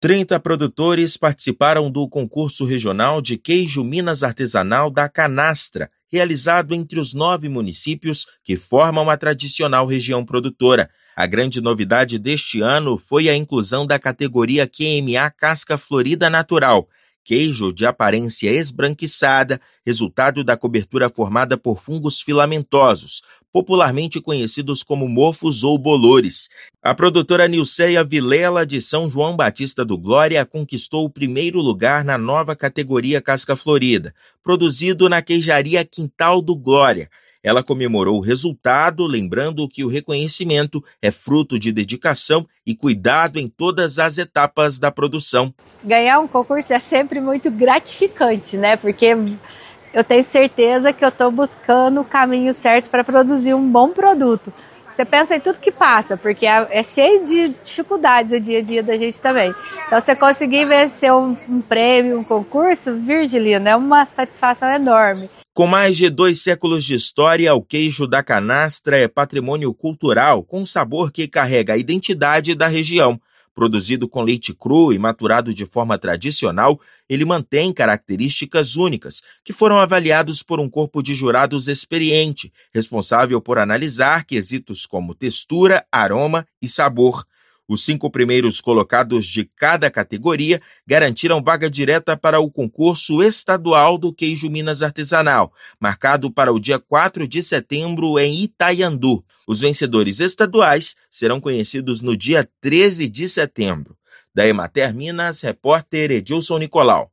Os cinco classificados representarão a região no 18º Concurso Estadual dos Queijos Artesanais de Minas Gerais. Ouça matéria de rádio.